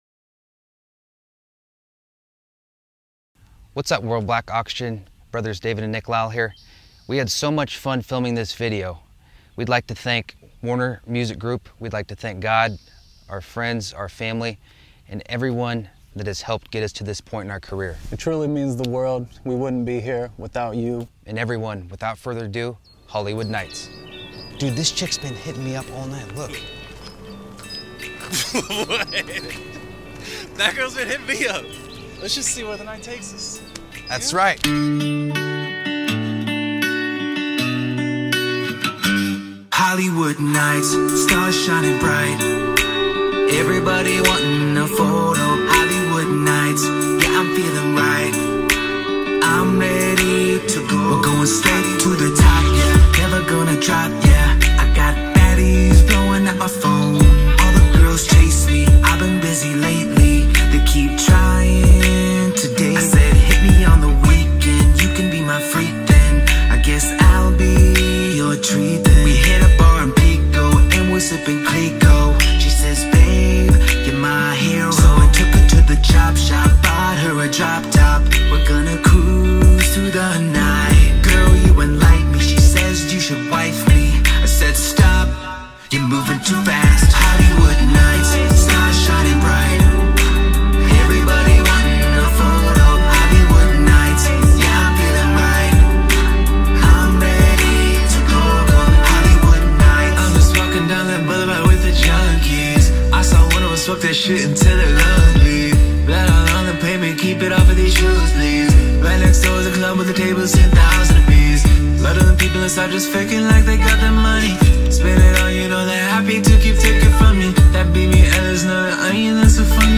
alternative rock/pop band
vocalist and lead guitarist
drums and keyboards
pop song